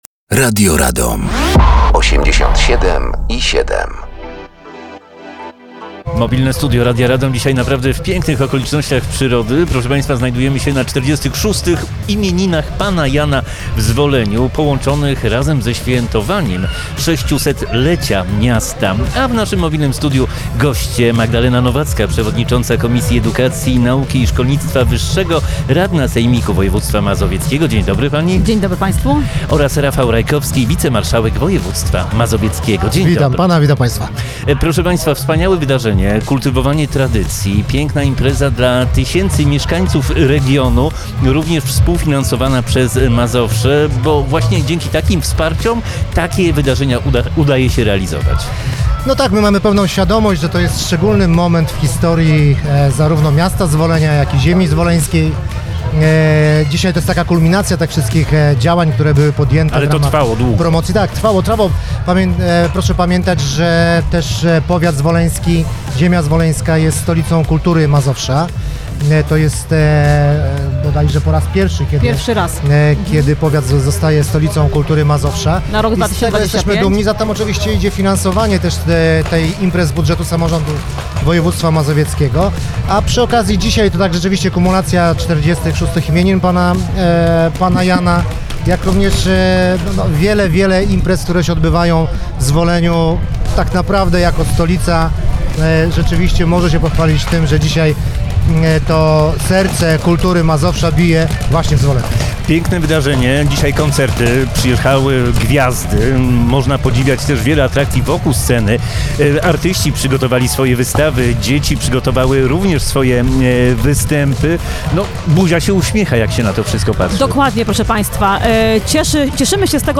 Mobilne Studio Radia Radom gościło w Zwoleniu podczas finałowego koncertu 46 Urodzin Pana Jana.
Gośćmi byli Magdalena Nowacka Przewodnicząca Komisji Edukacji Nauki i Szkolnictwa Wyższego, Radna Sejmiku Województwa Mazowieckiego
i  Rafał Rajkowski Wicemarszałek Województwa Mazowieckiego.